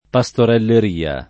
pastorelleria [ pa S toreller & a ] s. f.